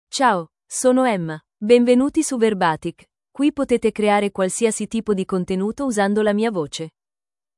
EmmaFemale Italian AI voice
Emma is a female AI voice for Italian (Italy).
Voice sample
Listen to Emma's female Italian voice.
Emma delivers clear pronunciation with authentic Italy Italian intonation, making your content sound professionally produced.